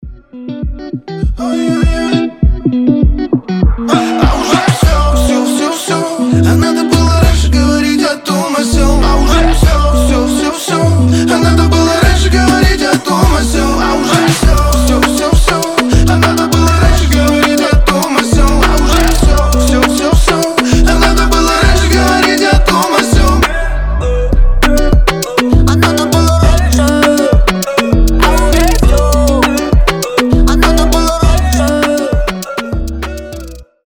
Рэп рингтоны , Танцевальные рингтоны